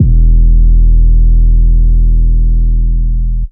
808s
808 5 {E} [ Agent ].wav